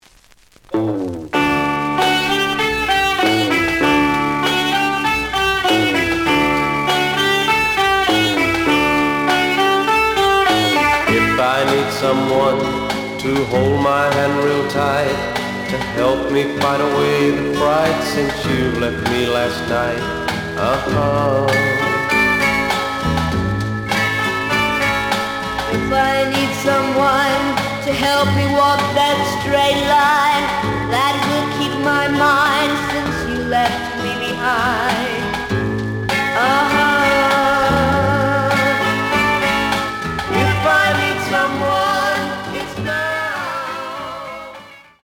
The audio sample is recorded from the actual item.
●Genre: Rock / Pop
Looks good, but slight noise on both sides.)